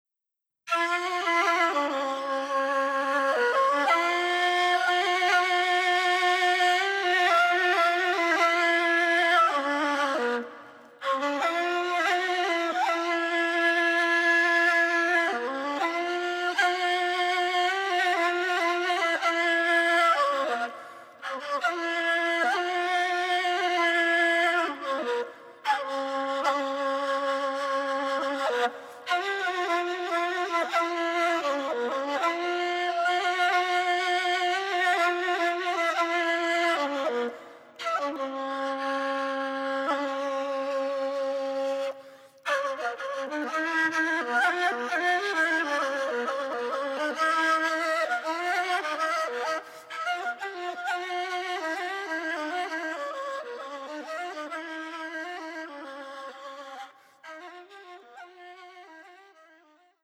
traditional Bulgarian kaval music